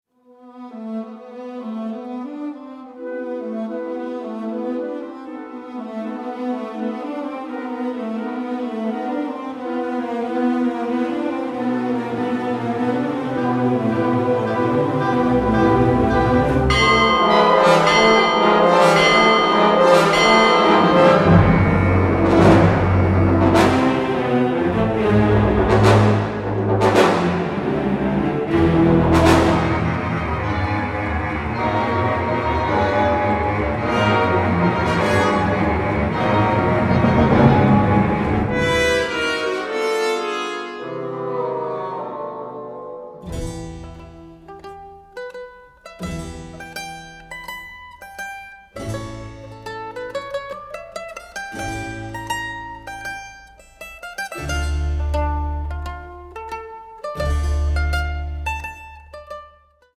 Recorded on the OSE orchestra stage in September 2018